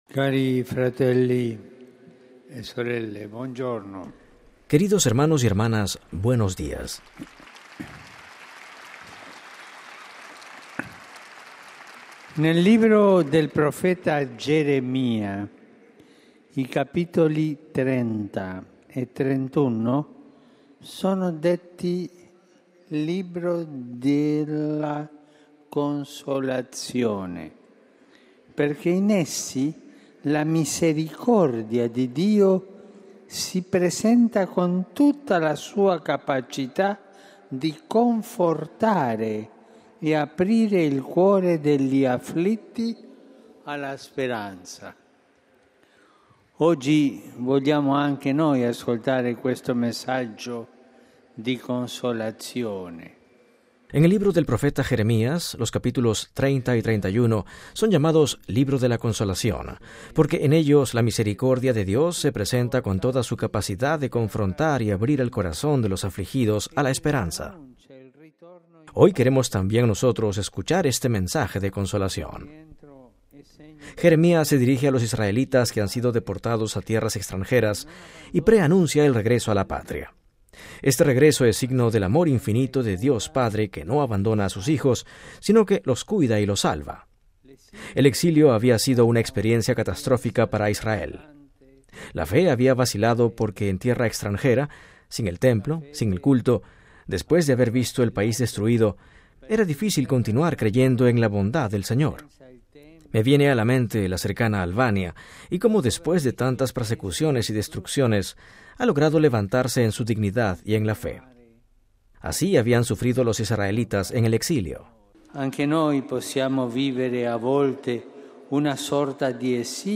Texto y audio completo de la catequesis del Papa Francisco